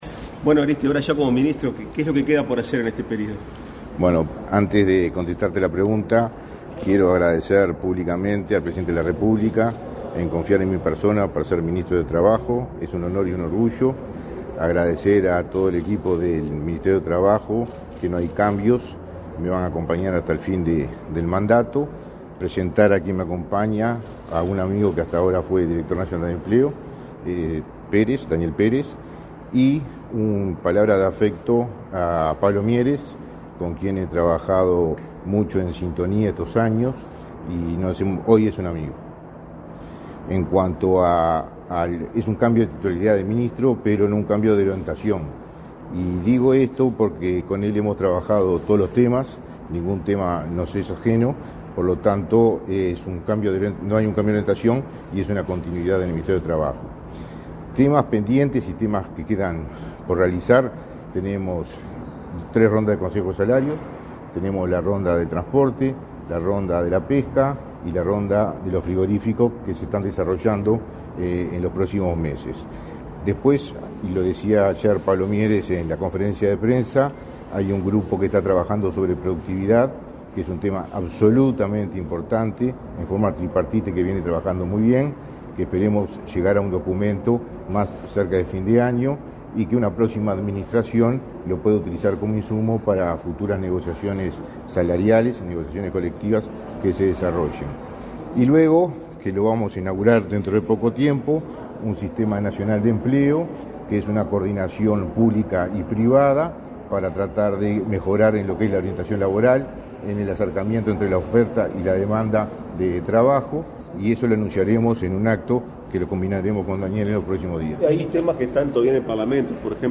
Declaraciones a la prensa del ministro de Trabajo y Seguridad Social, Mario Arizti